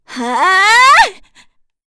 FreyB-Vox_Casting2.wav